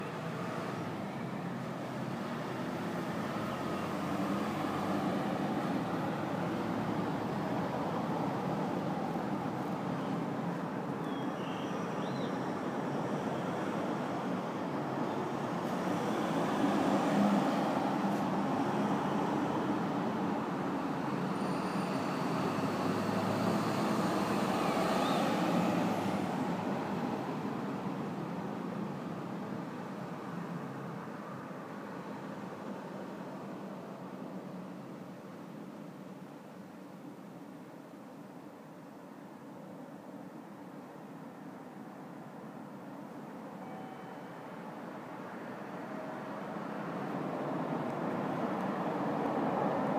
一羽早起きの鳥のさえずりに
聞こえてくる自然の囁き。